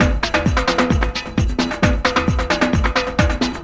Index of /90_sSampleCDs/E-MU Formula 4000 Series Vol. 2 – Techno Trance/Default Folder/Tribal Loops X
TRIBAL LO00L.wav